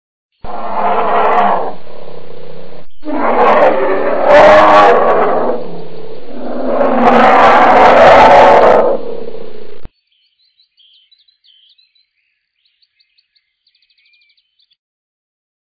Bär
Bären sind bekannt für ihr Brummen. Der Braunbär ist in den Hochgebirgen sehr selten geworden.
baer.mp3